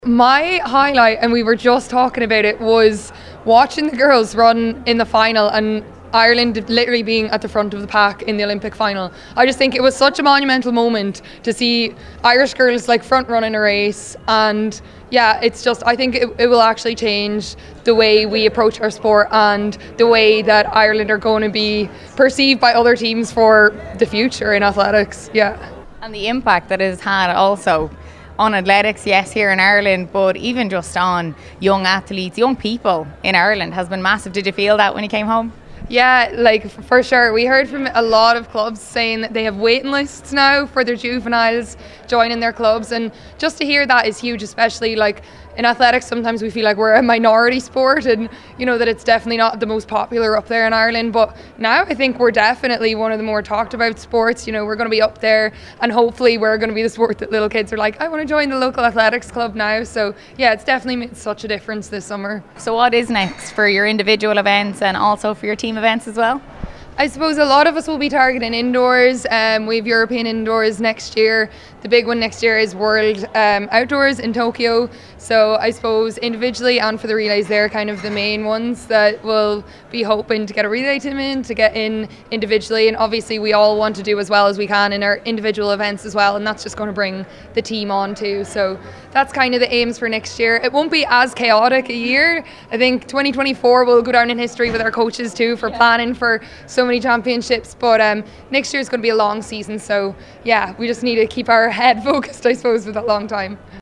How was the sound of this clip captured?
at Wednesday’s ceremony